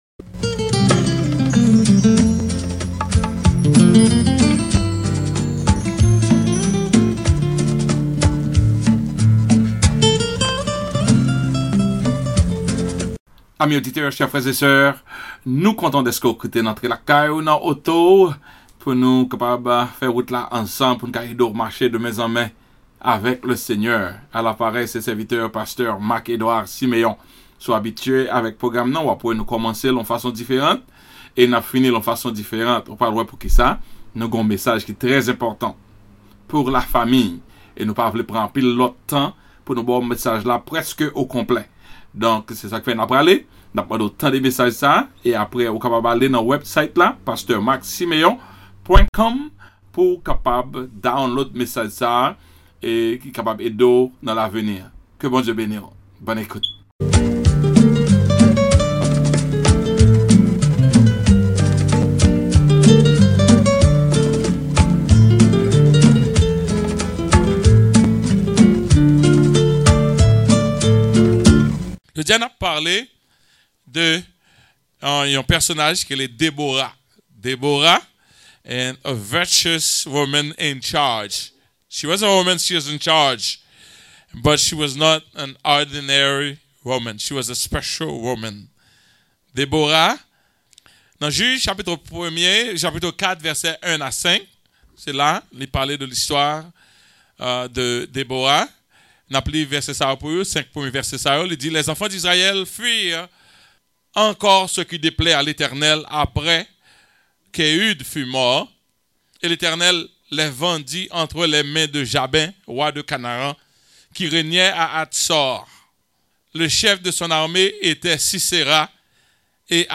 Please click here to download the sermon: DEBORA A VIRTUOUS WOMAN IN CHARGE